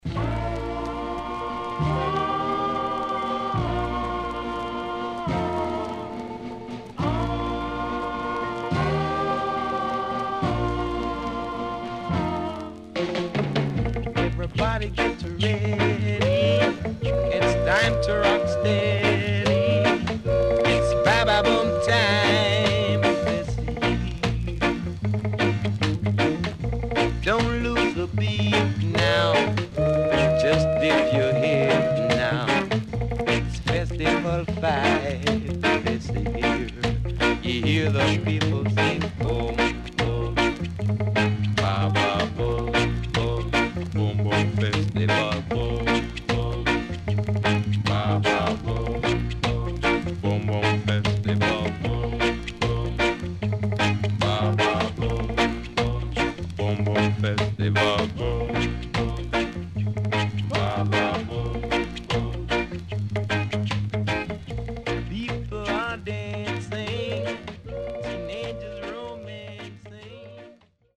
CONDITION SIDE A:VG(OK)
Good Rocksteady Inst & Rocksteady Foundation
SIDE A:プレス起因によるノイズ入ります。